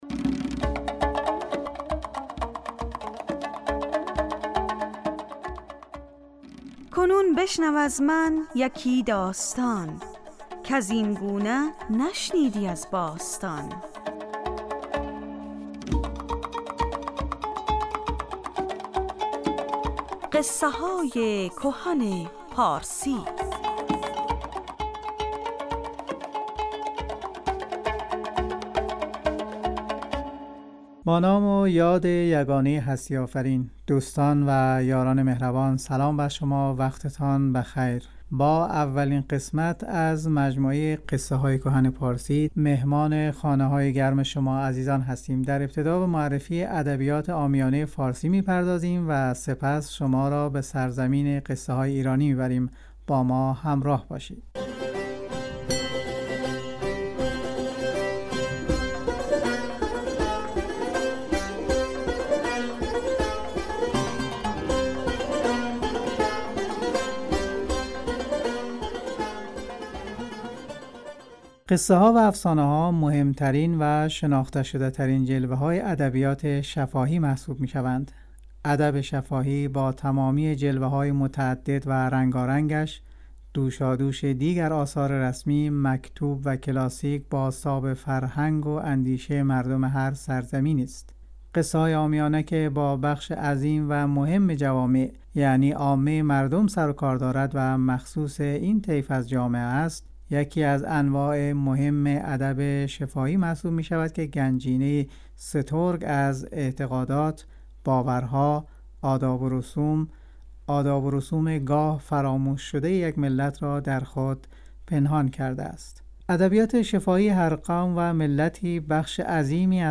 در بخش اول این برنامه به ادبیات پارسی پرداخته می شود و در بخش دوم یکی از داستان های هزار و یک شب روایت می شود.